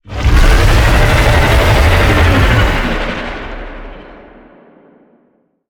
Sfx_creature_iceworm_roar_02.ogg